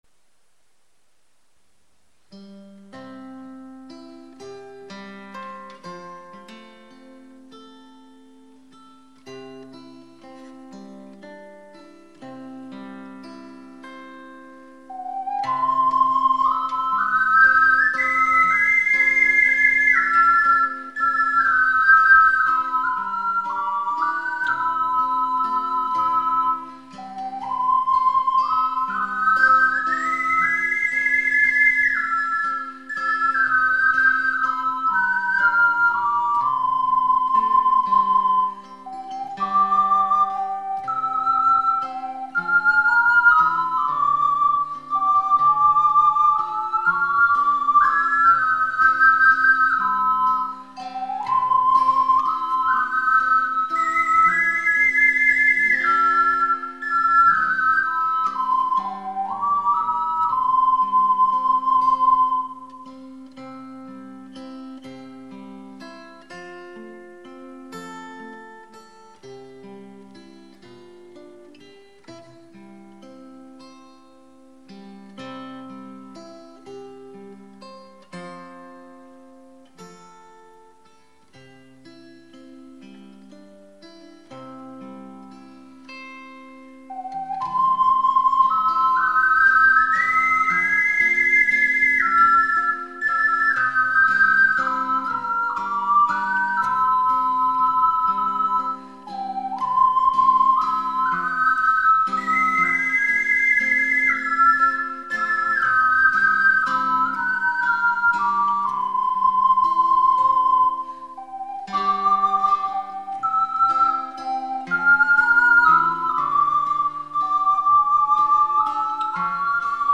21年前に録音した「オカリナデュオ 響」の音源です。